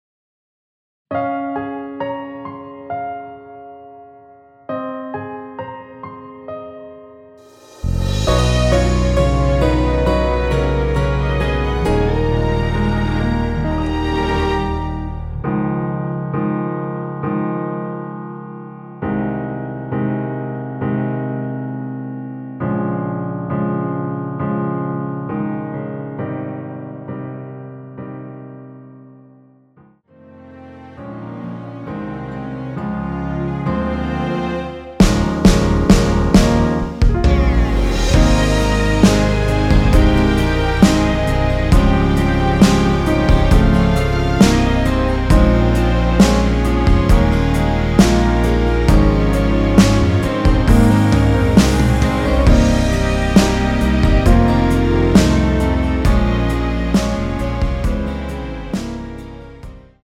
원키에서(-4)내린 1절앞+후렴으로 진행되는 MR입니다.
Ab
앞부분30초, 뒷부분30초씩 편집해서 올려 드리고 있습니다.
중간에 음이 끈어지고 다시 나오는 이유는